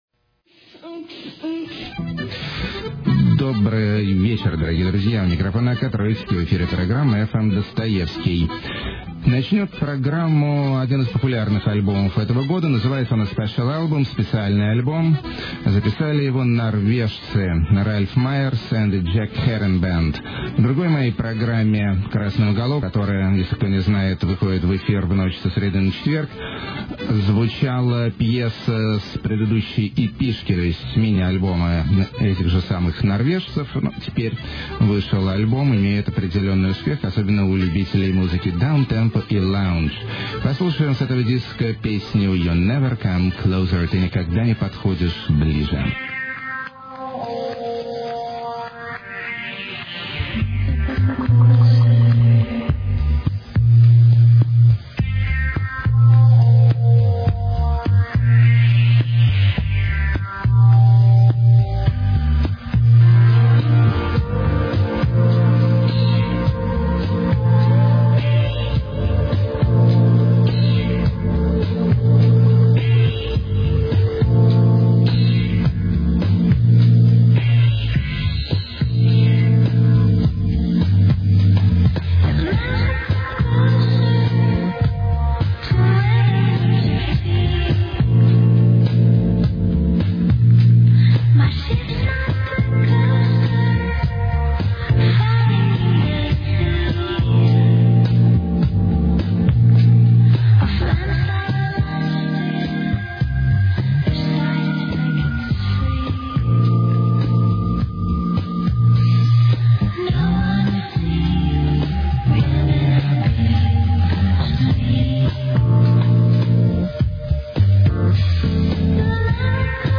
Totally Surrealistic Take On 50s And Sampladelica
Hungofolk With Progrock Hints
Unusually Dramatic Instrumentals
Playful Ironic Ska
Great Peruvian Surf Beat Archive
Breezy Female Krautpop
The Sleaziest Side Of Indierock
Punks Play Lush Lounge Exotica